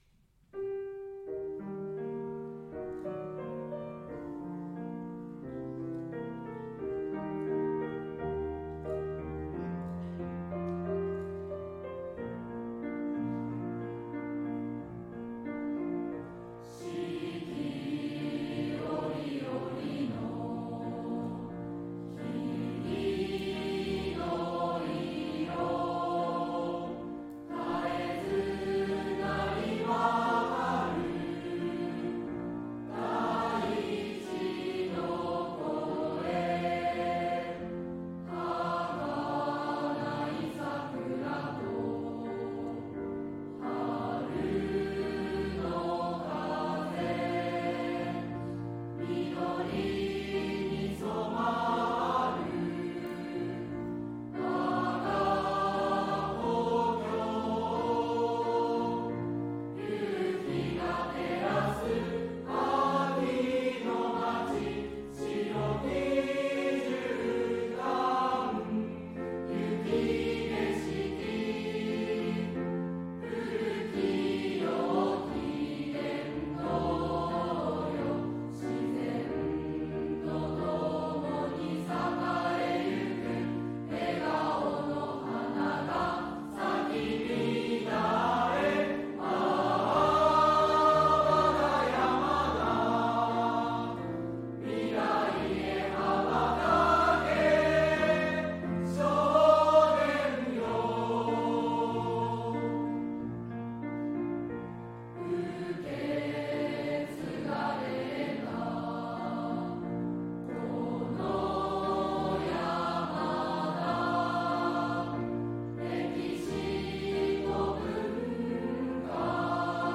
壮大なメロディーに乗せて山田地域の自然と文化、歴史を歌い上げています。